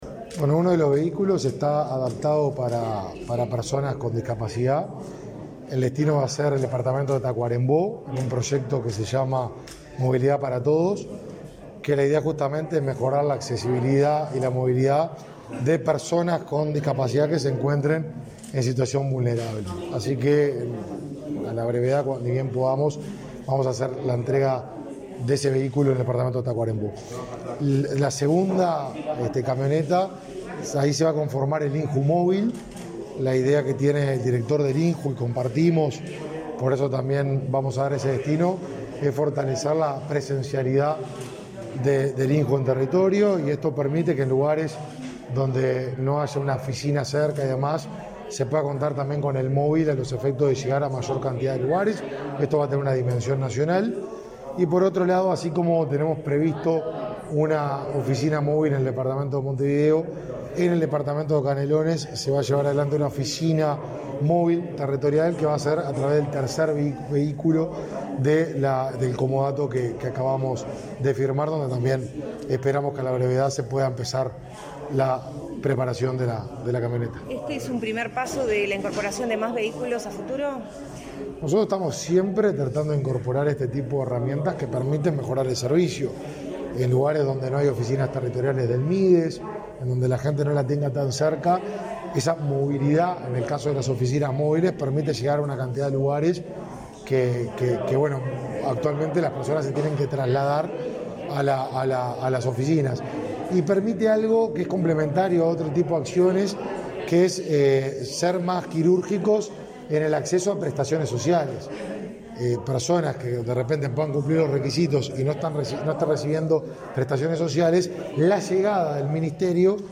Declaraciones a la prensa del ministro de Desarrollo Social
Declaraciones a la prensa del ministro de Desarrollo Social 16/12/2021 Compartir Facebook X Copiar enlace WhatsApp LinkedIn El titular de Desarrollo Social, Martín Lema, participó en la firma de un comodato con la empresa Fidocar, mediante el cual el ministerio dispondrá de tres camionetas, una de ellas adaptada para transportar personas con discapacidad. Luego, dialogó con la prensa.